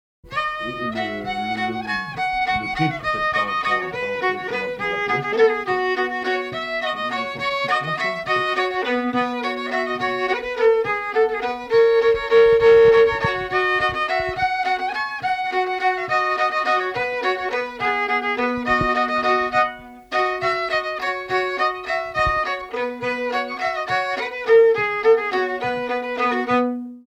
Analyste Note Chanson moderne du "néo-folklore" auvergnat.
Pièce musicale inédite